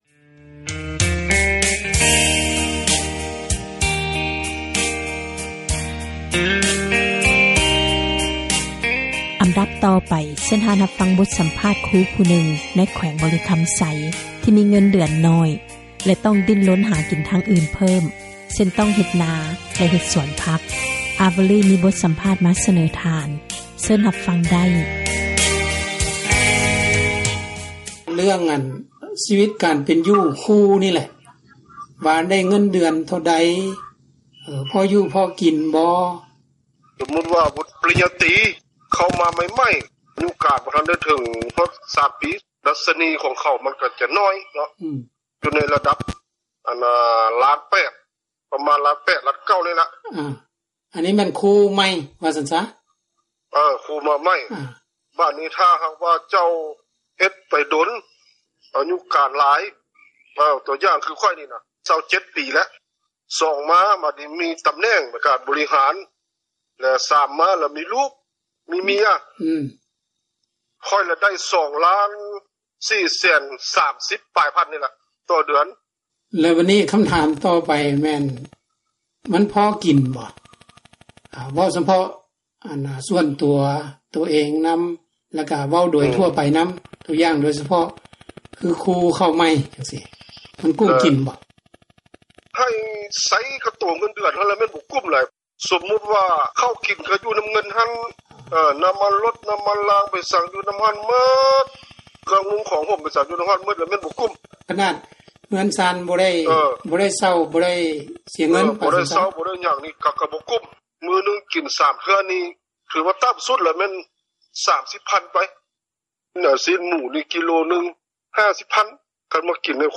ສັມພາດ ຄຣູຜູ້ນຶ່ງ ໃນ ແຂວງບໍຣິຄຳໄຊ ທີ່ມີເງິນເດືອນ ໜ້ອຍ ແລະ ຕ້ອງດິ້ນຮົນ ຫາກິນ ທາງອື່ນເພີ່ມ ເຊັ່ນ: ຕ້ອງເຮັດນາ ແລະ ສວນຄົວ.